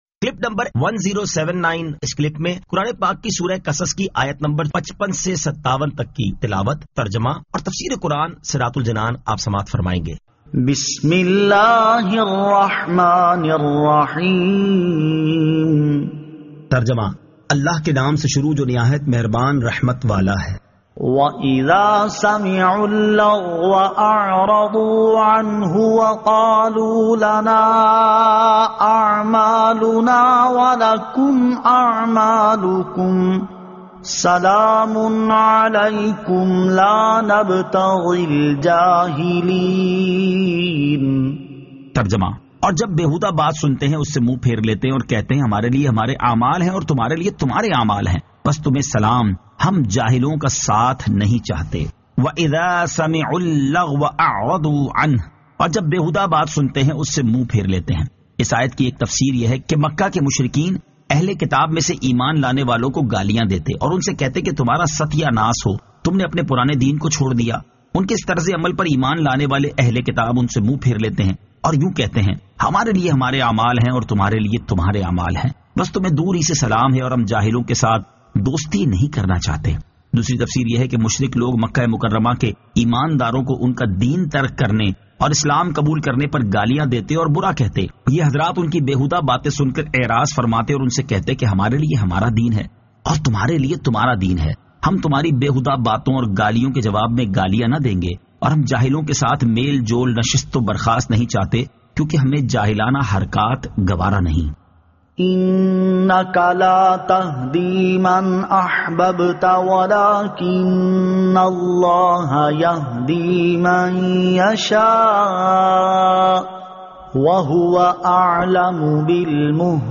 Surah Al-Qasas 55 To 57 Tilawat , Tarjama , Tafseer